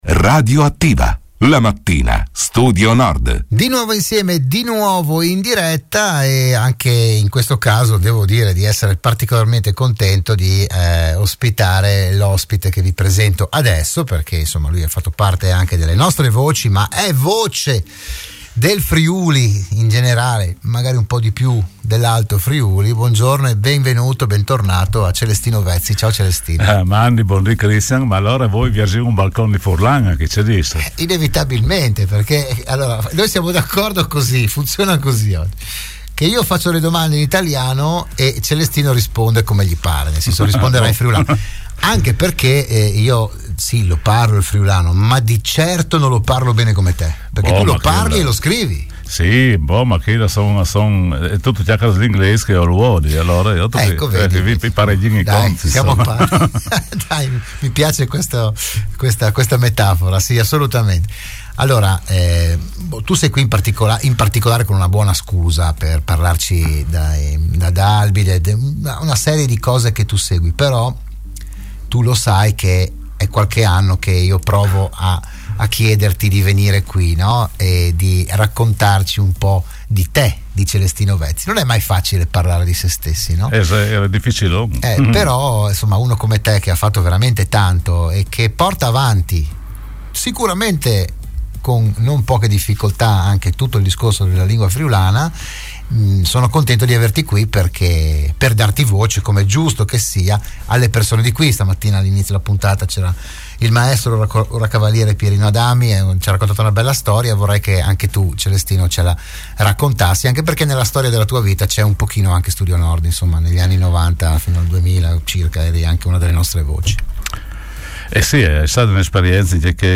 Ospite a "RadioAttiva" un appassionato ricercatore degli aspetti tradizionali, storici e culturali della Carnia